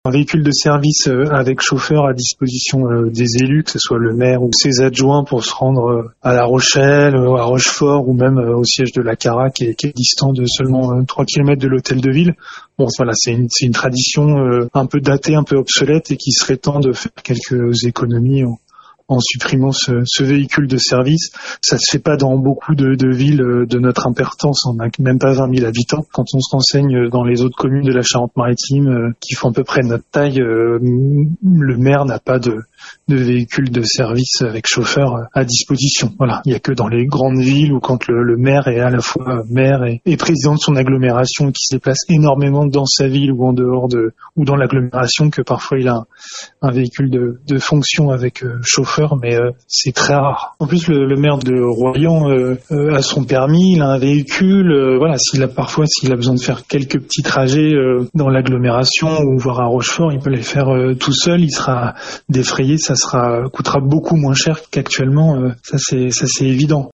Le conseiller municipal et communautaire d’opposition a évoqué le sujet lors du dernier conseil municipal :